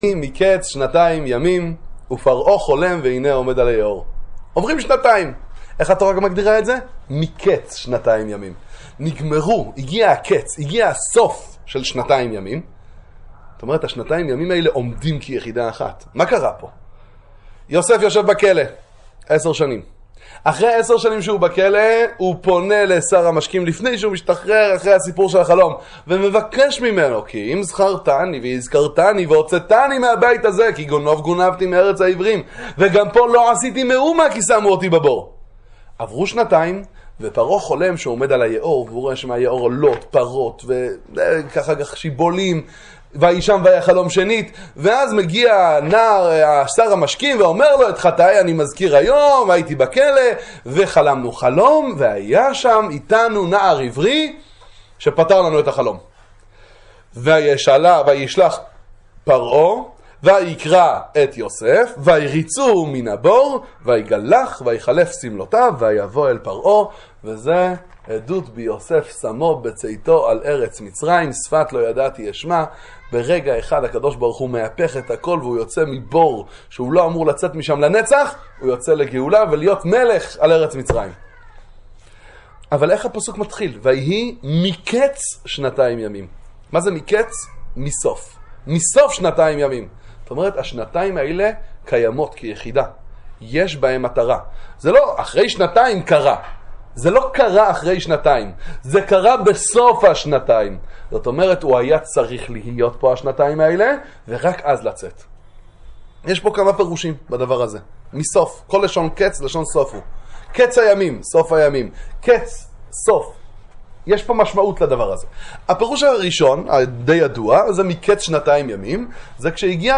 הכל מתוזמן… 'קץ שם לחושך' – דבר תורה קצר לפרשת מקץ